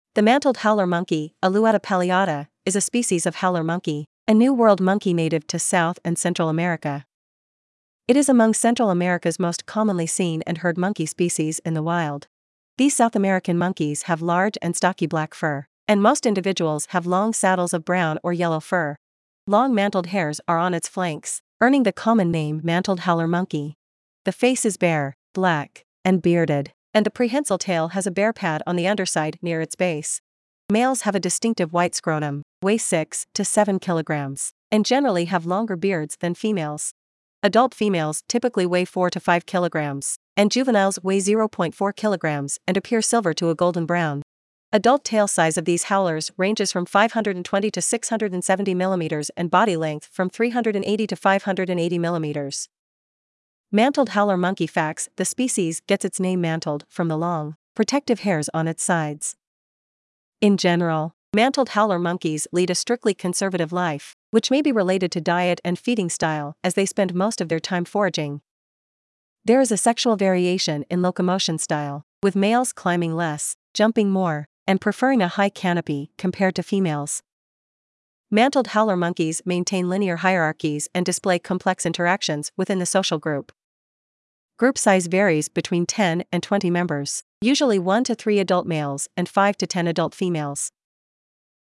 Mantled Howler Monkey
Mantled-Howler-Monkey.mp3